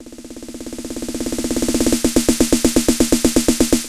SnareFill3-44S.wav